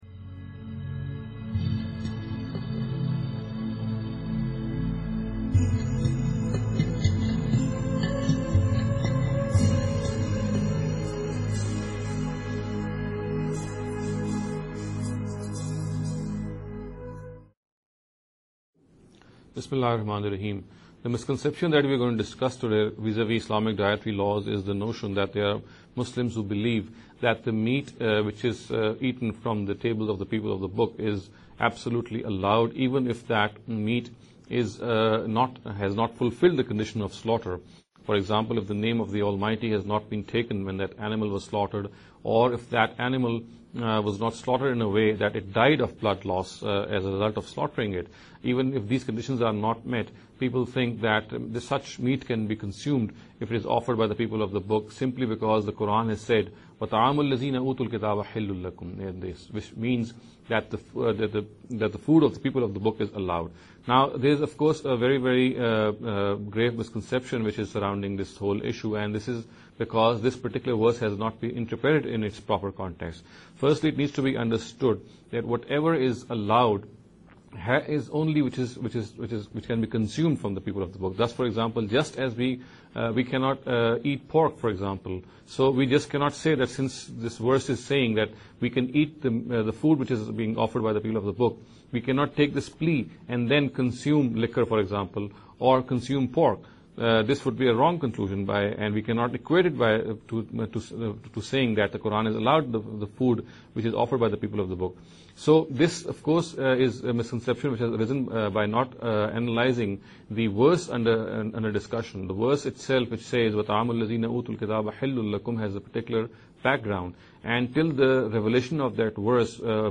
This lecture series will deal with some misconception regarding The Dietary Directives of Islam.